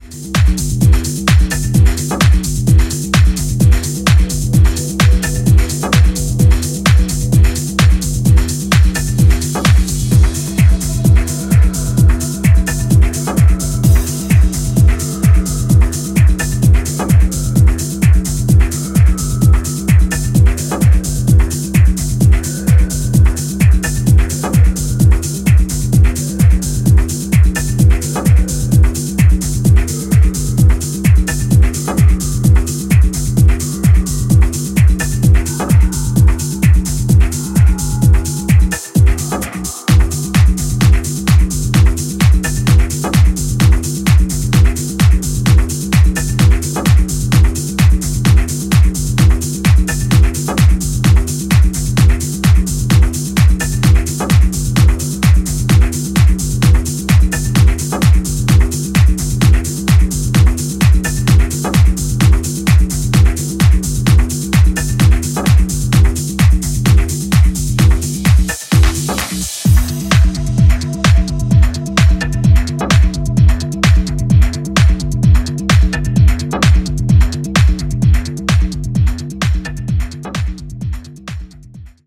アトモスフェリックな緊迫感が持続する